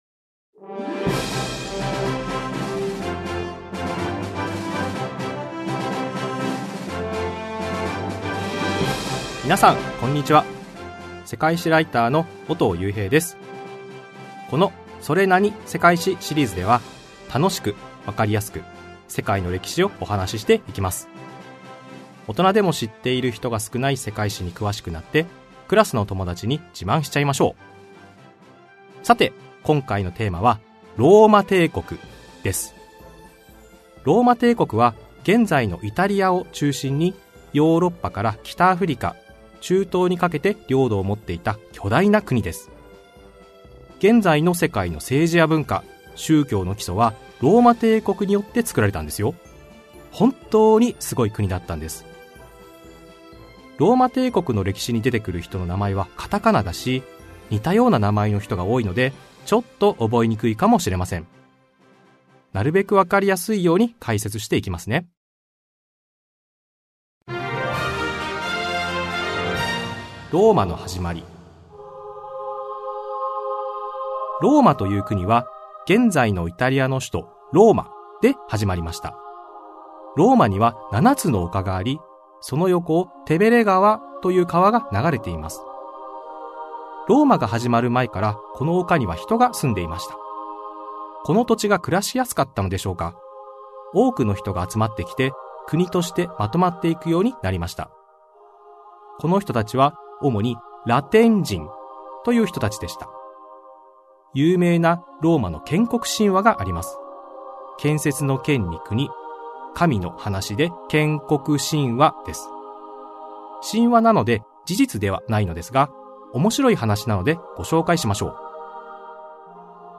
[オーディオブック] それなに？世界史 Vol.3 ローマ帝国